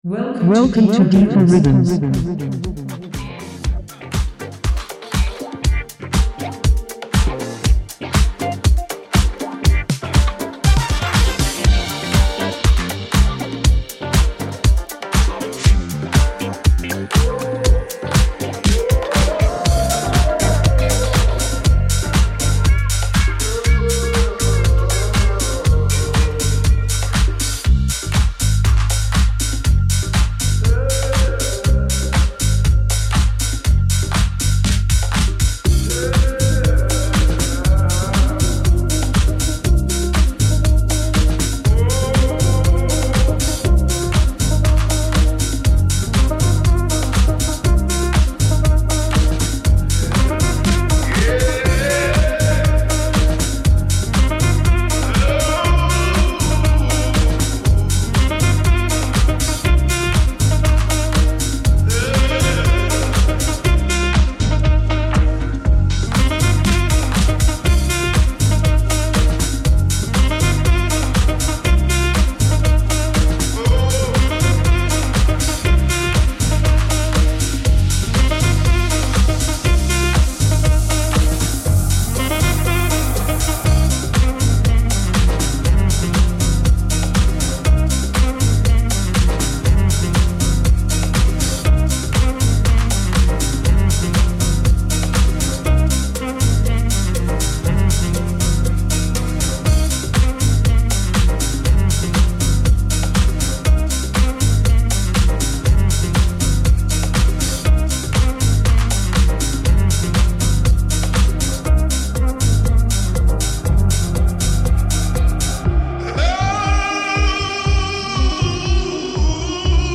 House, Techno, Disco, Soul, Funk, Hip-Hop